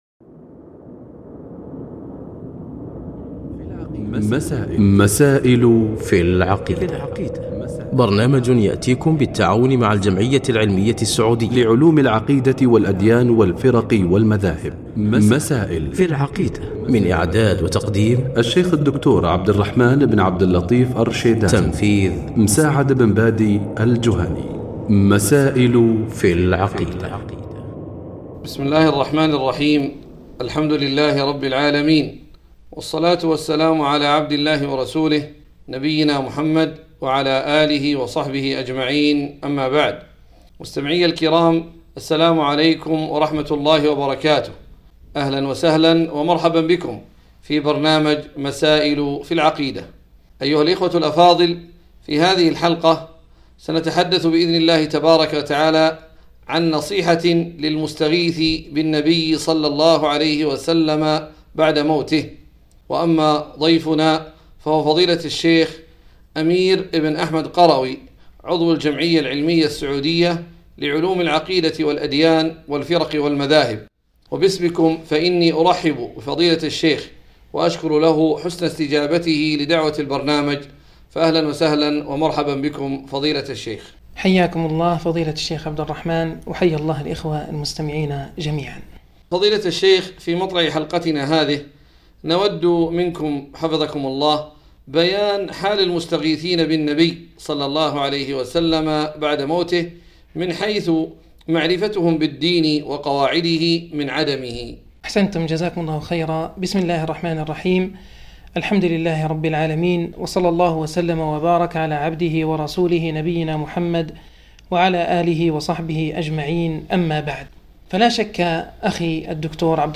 نصيحة للمستغيثين بالنبي صلى الله عليه وسلم - حلقة إذاعية